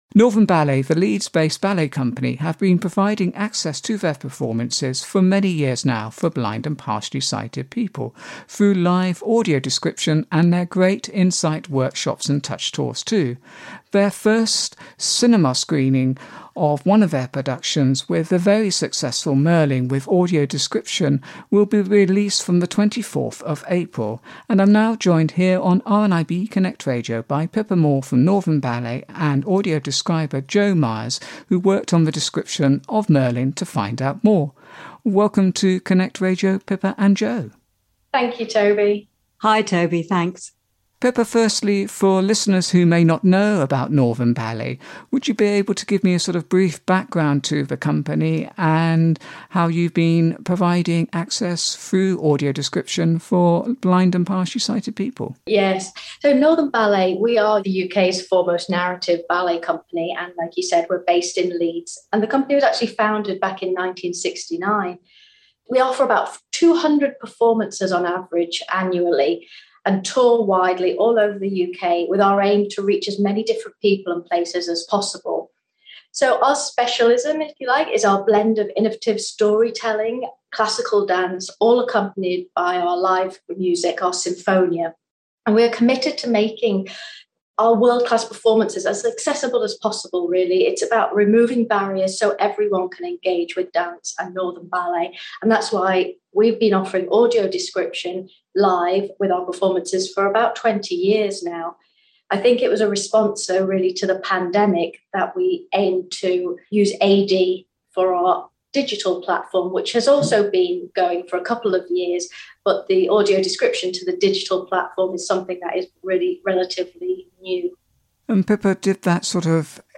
Northern Ballet, the Leeds based ballet company who have been providing access to their productions for over 20 years for blind and partially sighted people with live through audio description along with their In Sight dance workshops/touch tours, will have their first cinema screening of one of their productions with audio description when Merlin is released in cinemas from Sunday 24 April 2022. RNIB Connect Radio’s